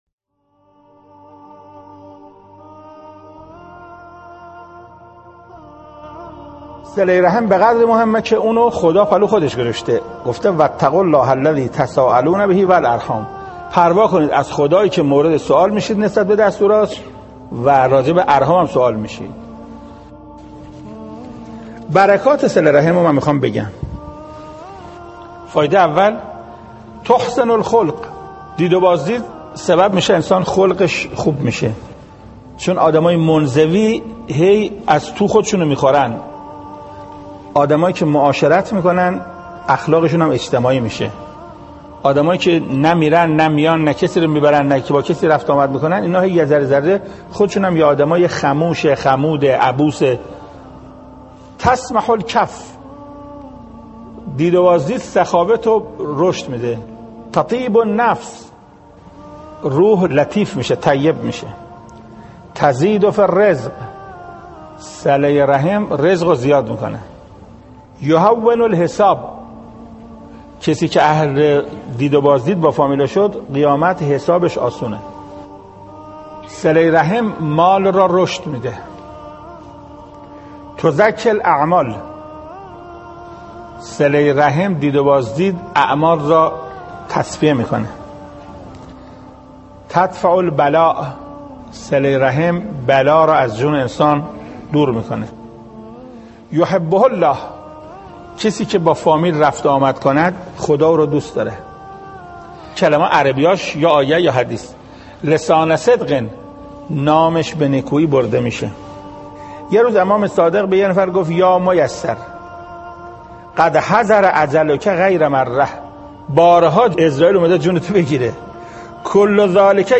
حجت‌الاسلام والمسلمین محسن قرائتی، مفسر قرآن و رئیس ستاد اقامه نماز کشور در یکی از سخنرانی‌های با استناد به آیات و روایات اسلامی به تبیین آثار صله رحم پرداخت.